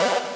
Computer Arp (1).wav